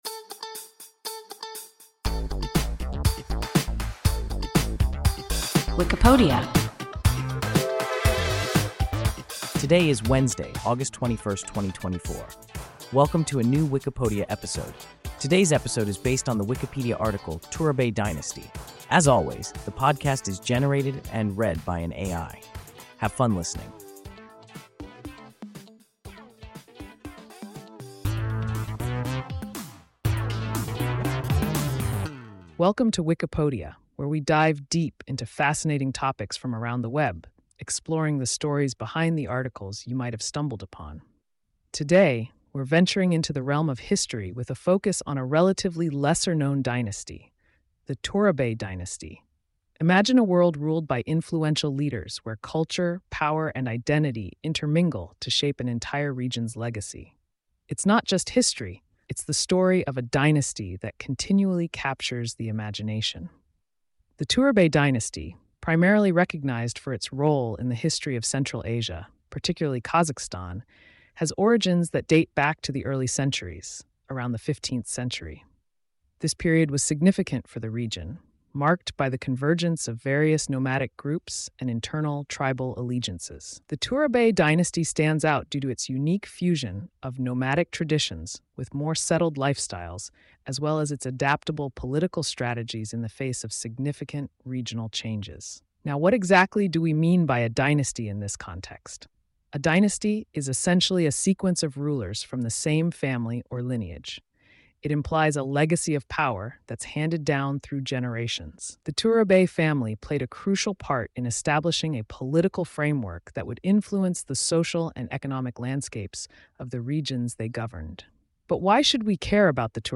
Turabay dynasty – WIKIPODIA – ein KI Podcast